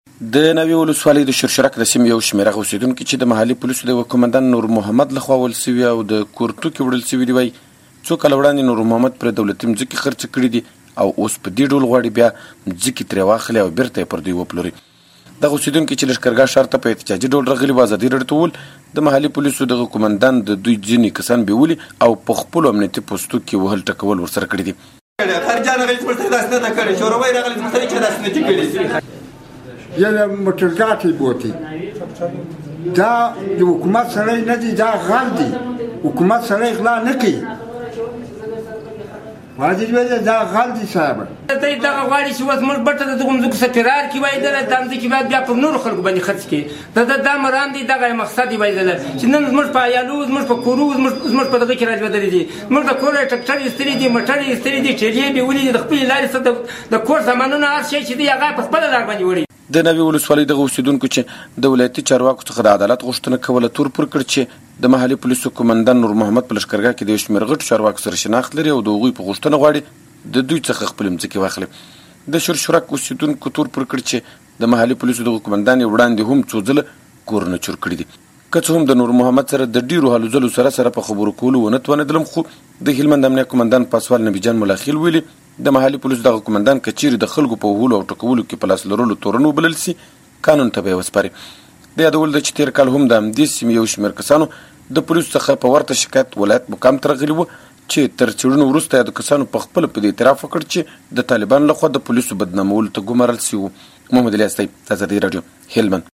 راپورونه